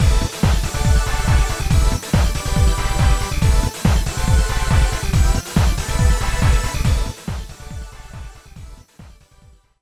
◆ステレオ音源をDPCM化すると定位がおかしくなるんじゃと思ったが以外と維持できているので結構使えるかも。
music_2bitdpcm.mp3